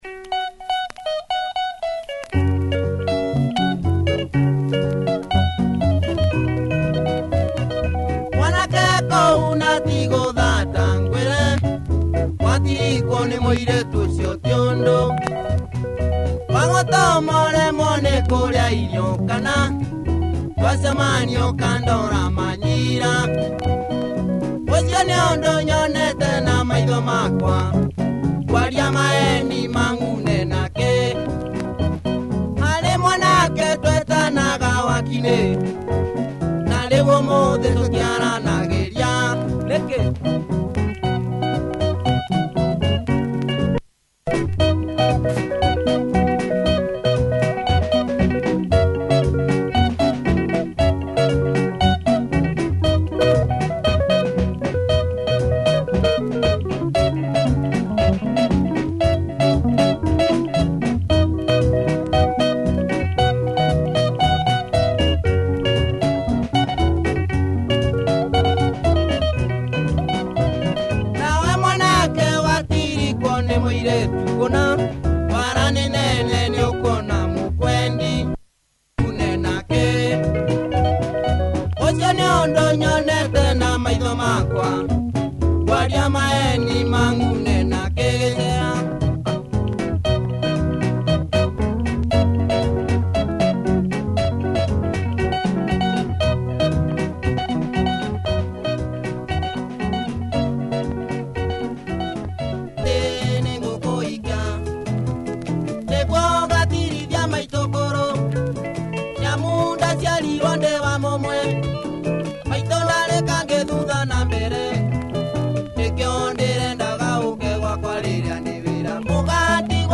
benga